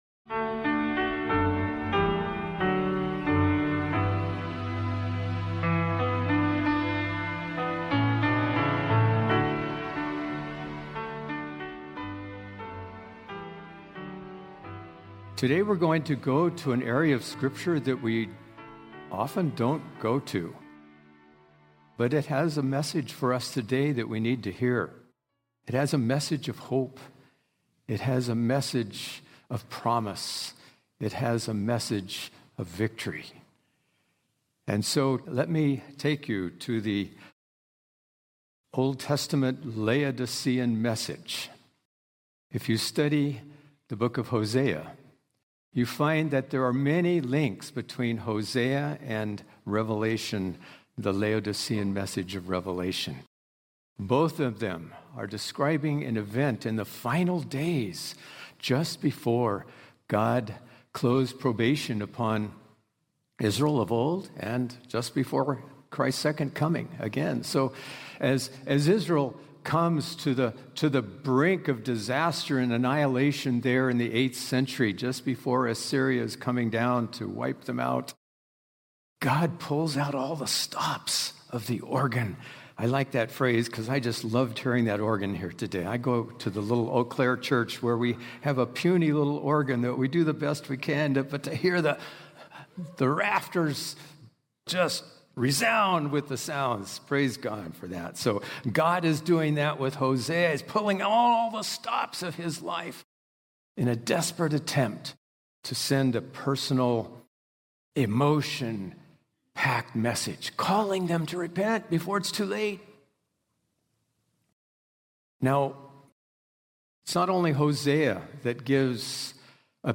This sermon reveals the powerful prophetic connection between Hosea and Revelation, showing how God’s steadfast love and justice call His people to repentance and renewal. Through vivid imagery—from Hosea’s broken marriage to the Valley of Achor turned into hope—it proclaims a message of restoration, unconditional love, and the transforming grace that redeems even the deepest failures.